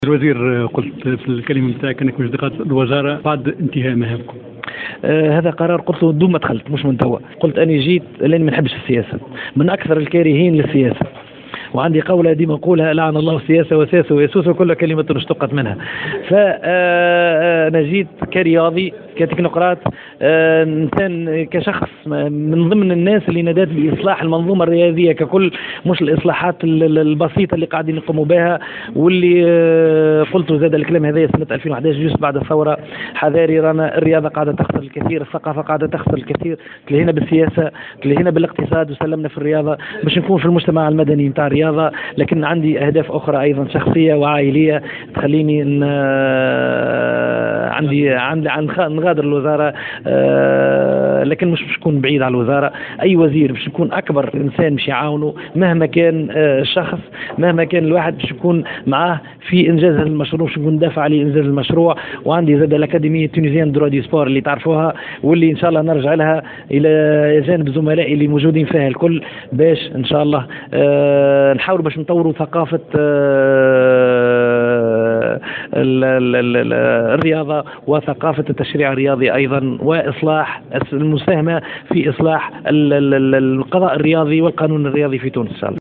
أعلن وزير الشباب و الرياضة و المرأة و الأسرة صابر بوعطي خلال فعاليات المنتدى الوطني حول الرياضة و الرياضات المنعقد صباح اليوم الاربعاء 10 ديسمبر عن انتهاء مهامه على رأس الوزارة بانتهاء فترة الحكومة الحالية.